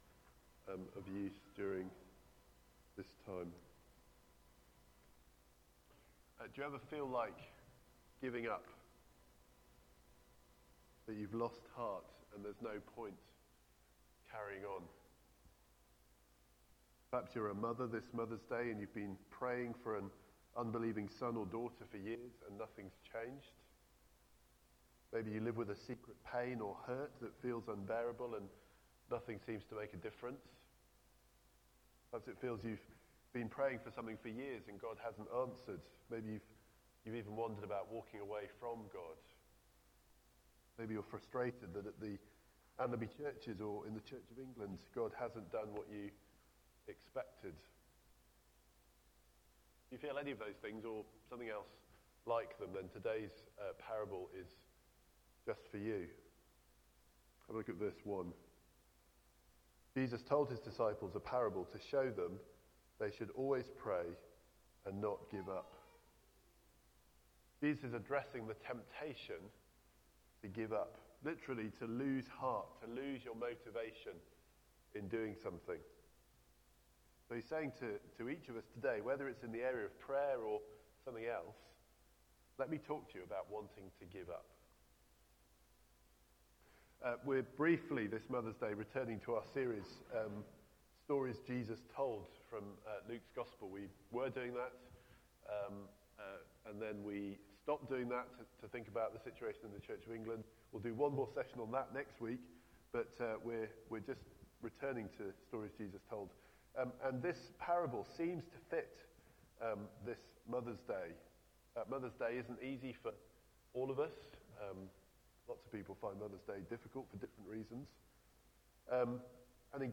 Media Library The Sunday Sermons are generally recorded each week at St Mark's Community Church.
Theme: Sermon